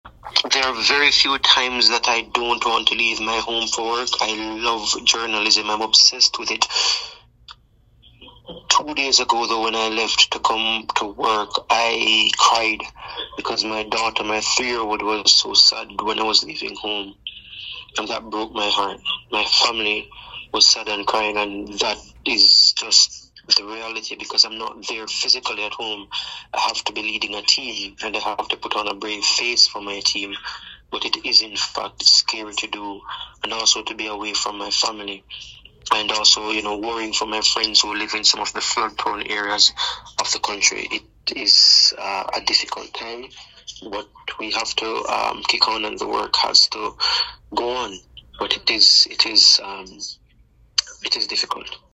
Esto es solo el principio“, aseguró mediante llamada telefónica.